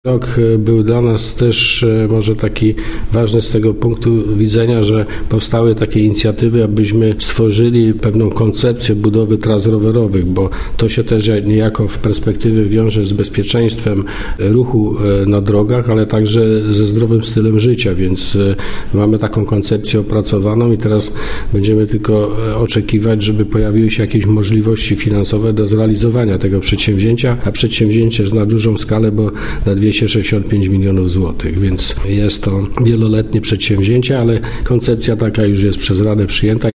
– mówił starosta powiatu wieruszowskiego, Andrzej Szymanek.